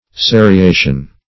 seriation - definition of seriation - synonyms, pronunciation, spelling from Free Dictionary Search Result for " seriation" : The Collaborative International Dictionary of English v.0.48: Seriation \Se`ri*a"tion\, n. (Chem.)